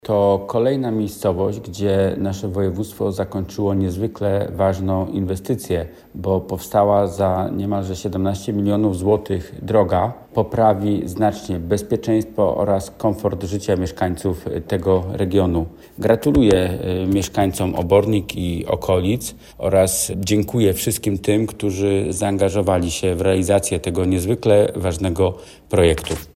Mówi Piotr Karwan – radny Sejmiku Województwa Dolnośląskiego.